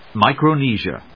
音節Mi・cro・ne・sia 発音記号・読み方
/mὰɪkrəníːʃə(米国英語), ˌmaɪ.kɹəʊˈniː.zi.ə(英国英語)/